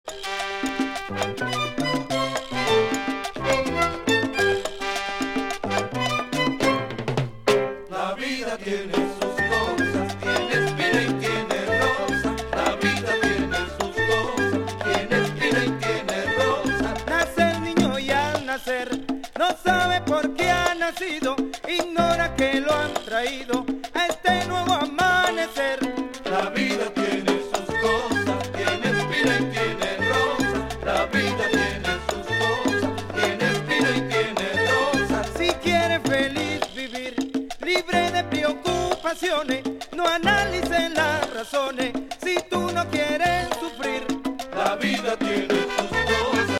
1972 Género: Latin Estilo: Salsa, Guaguancó